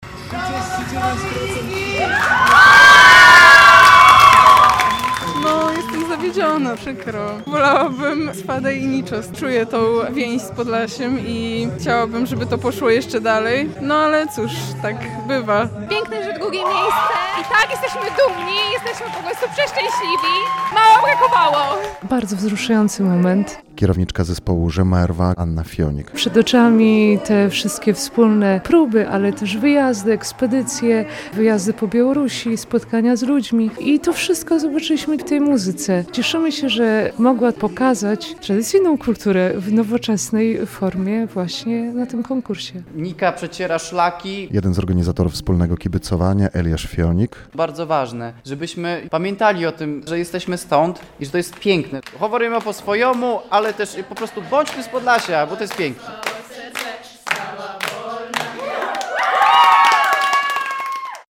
Wspólne oglądanie finału preselekcji Konkursu Piosenki Eurowizji w Kreatywnym Centrum Kultury w Bielsku Podlaskim - relacja